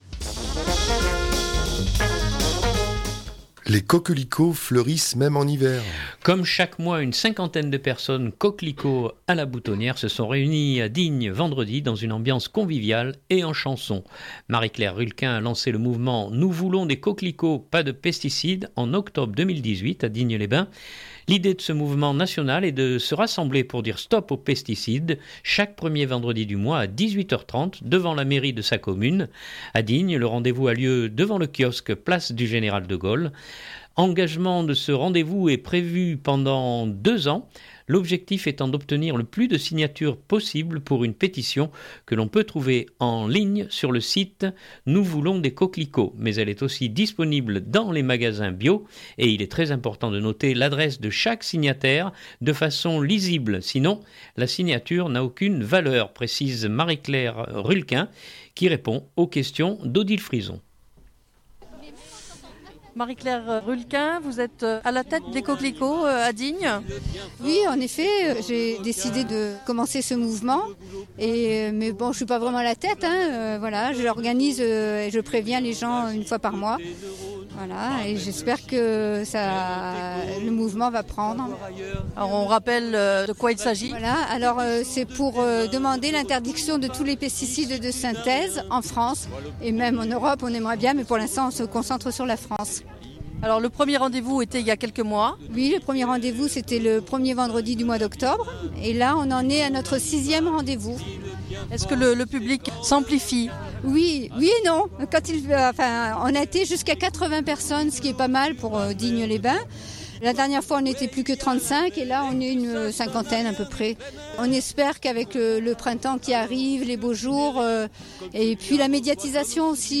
Comme chaque mois, une cinquantaine de personnes, coquelicot à la boutonnière, se sont réunies à Digne vendredi dans une ambiance conviviale et en chanson.